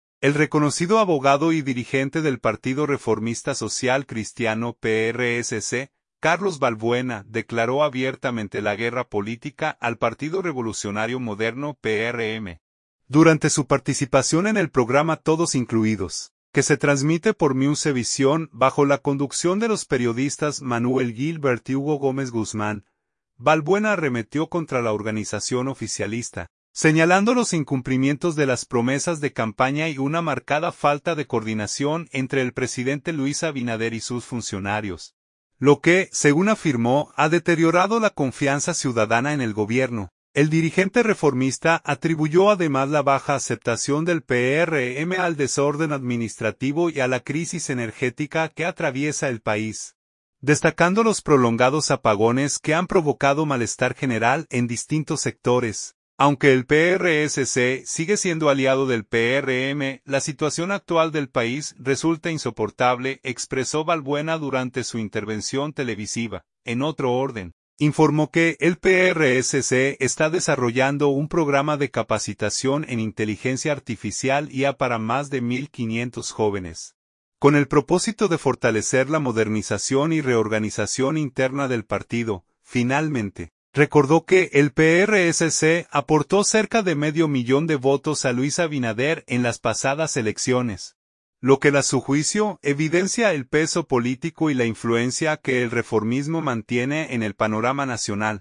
durante su participación en el programa Todos Incluidos, que se transmite por Musa Visión